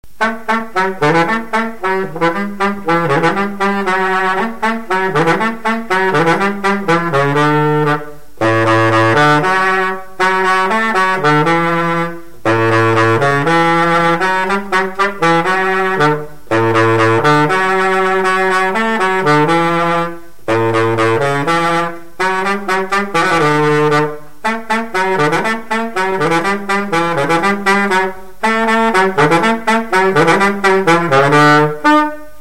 Usage d'après l'informateur gestuel : danse
Pièce musicale inédite